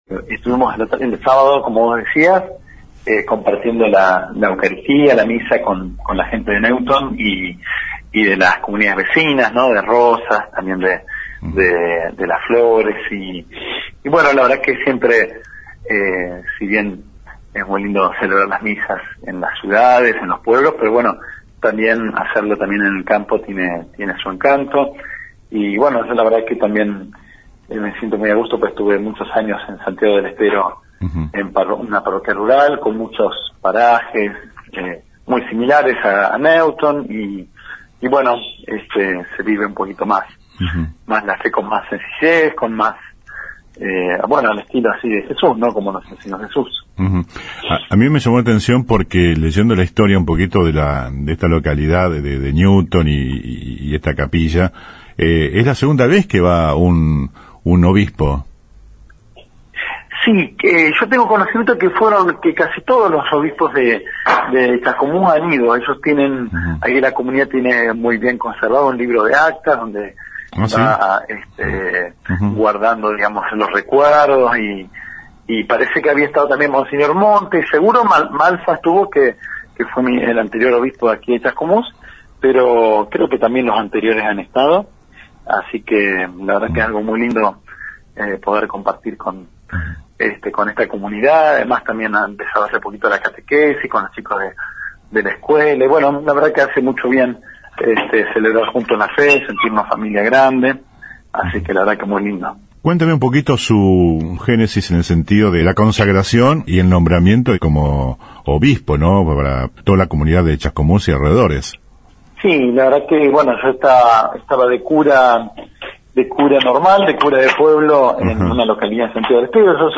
En LA RADIO, hablamos con Liébana.
AUDIO COMPLETO DE LA ENTREVISTA A MOSEÑOR JUAN IGNACIO LIÉBANA